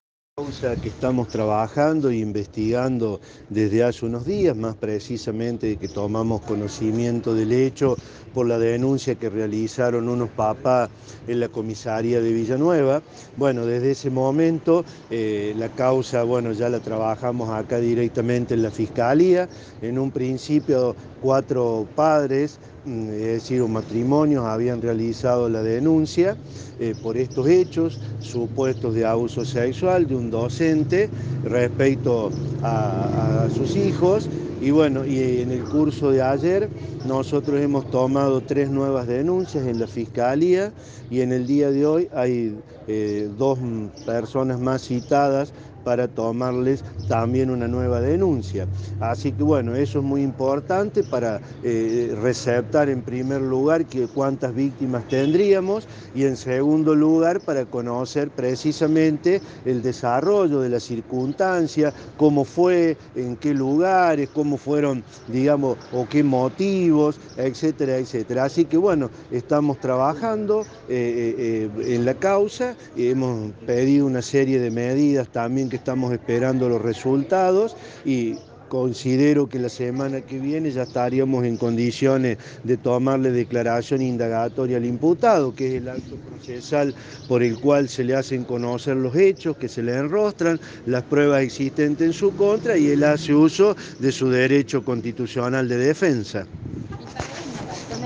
En relación a la causa el fiscal Rene Bosio decía lo siguiente: